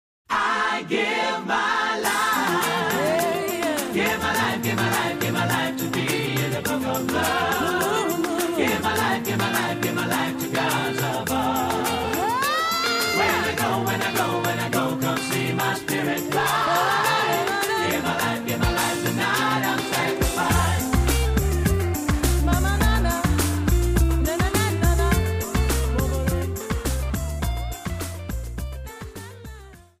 Samba